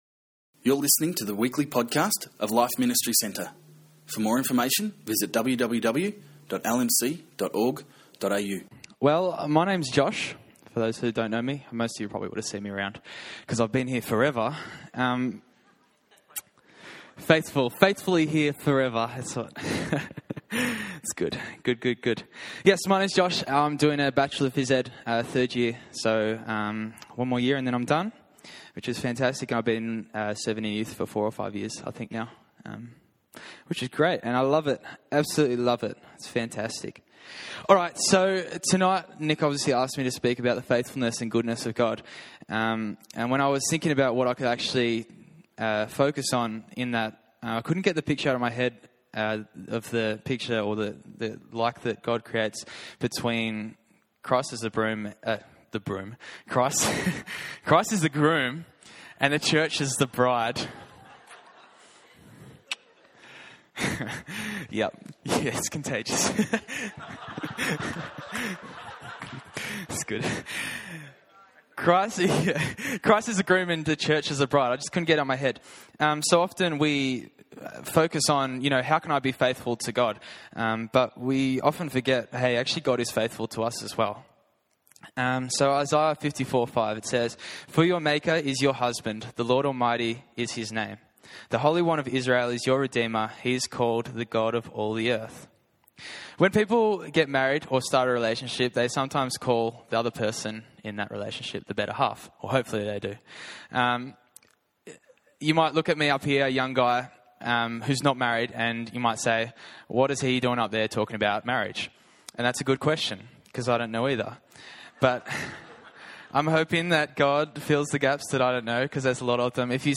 Youth Service: Faithfulness